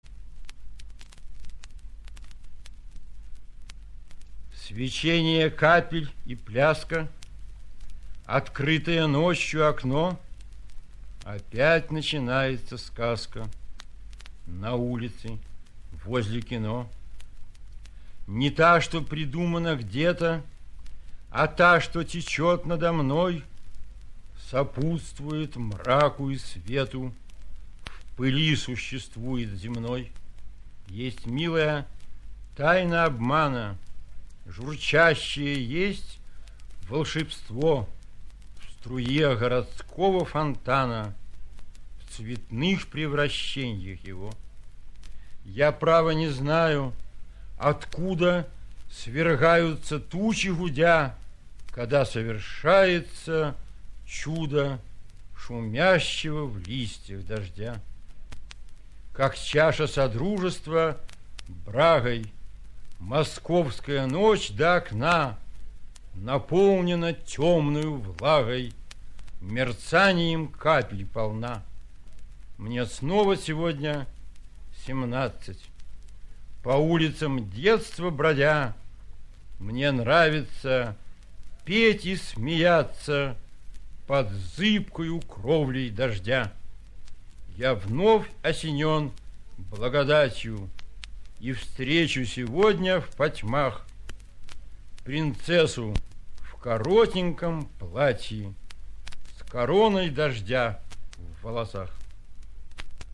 2. «Ярослав Смеляков – Опять начинается сказка… (читает автор)» /
yaroslav-smelyakov-opyat-nachinaetsya-skazka-chitaet-avtor